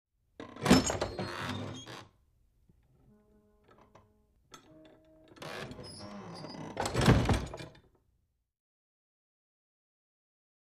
Heavy Wood Door Open With Metal Latch Creaks, Wood Squeaks And Slow Close